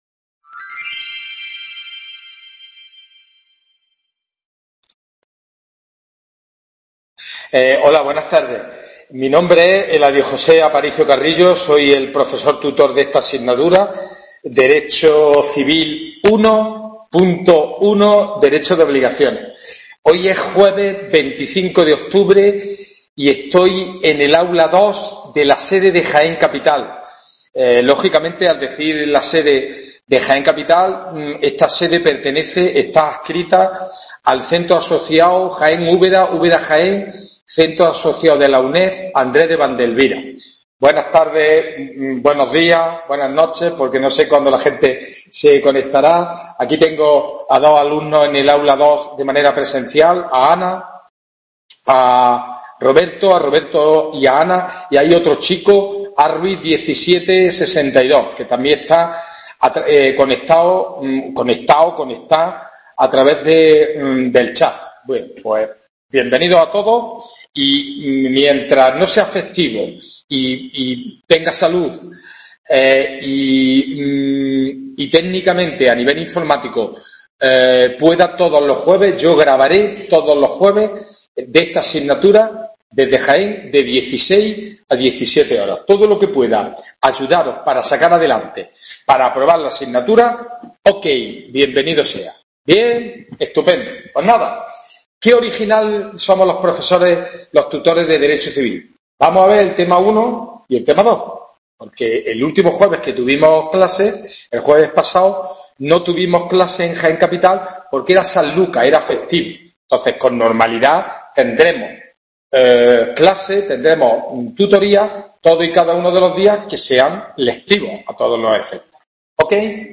WEB CONFERENCIA "Dº CIVIL II.1: "Dº DE OBLIGACIONES" …